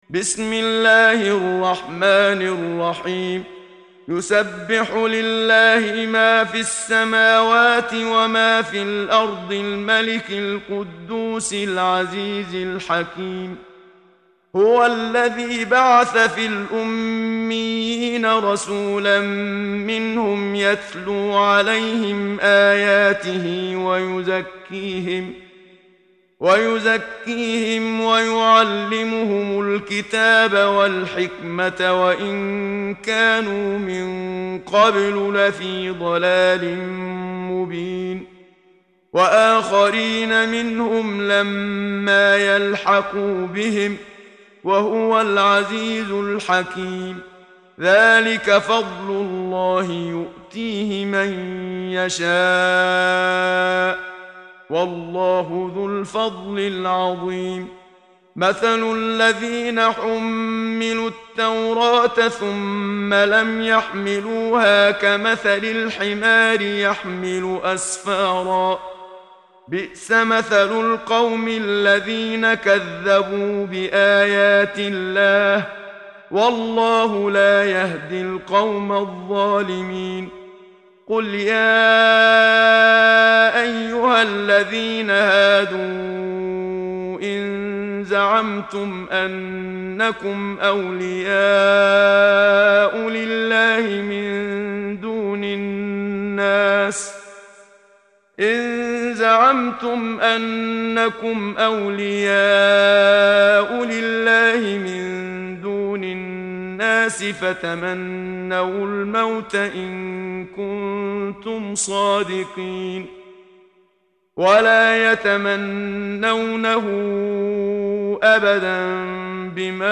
محمد صديق المنشاوي – ترتيل